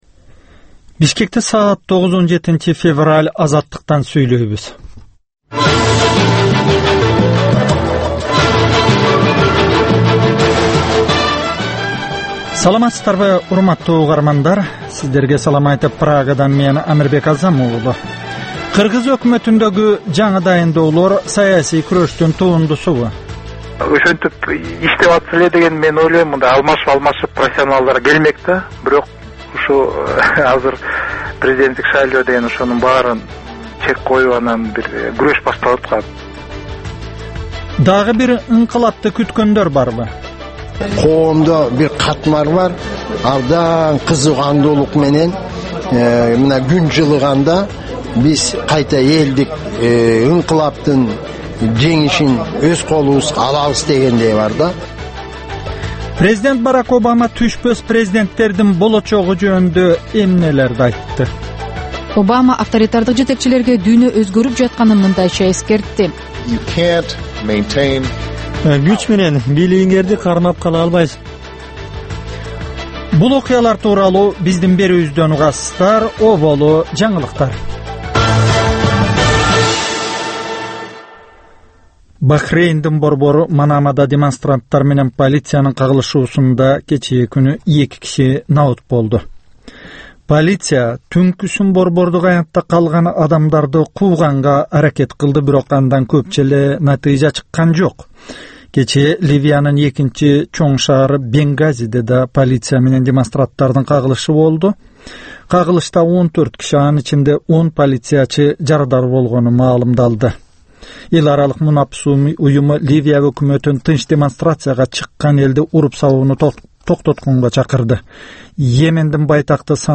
Таңкы 9дагы кабарлар